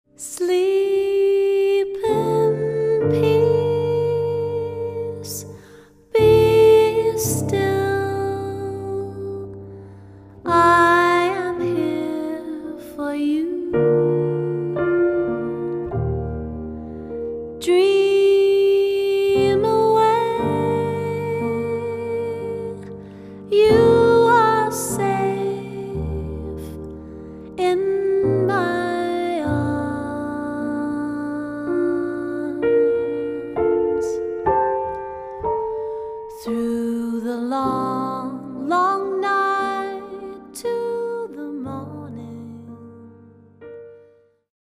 duet album between pianist
Audio previews